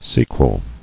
[se·quel]